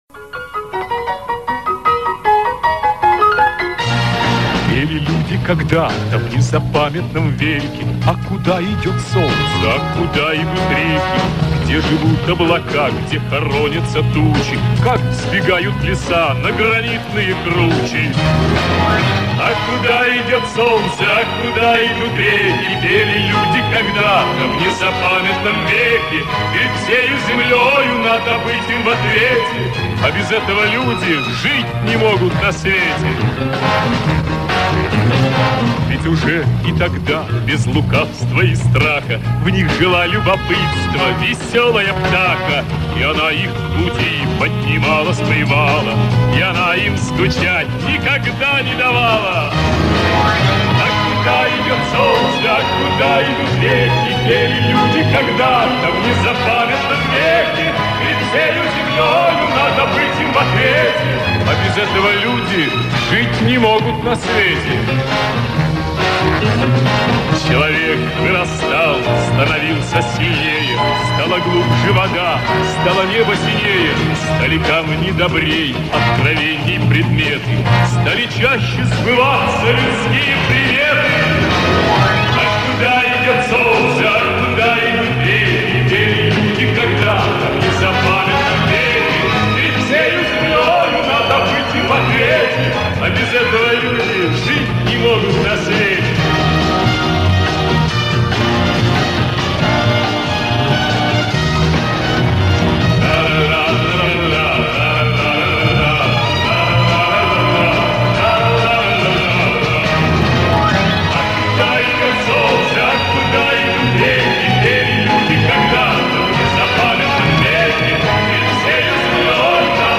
Местами голос похож на Хиля.